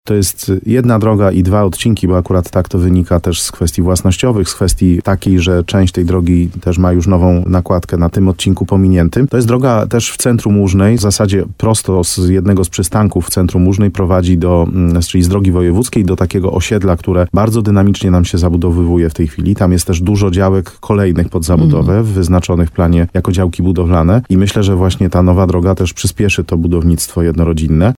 Jak mówi wójt Mariusz Tarsa – te tereny to miejsce, w którym w ostatnich latach powstało wiele nowych domów.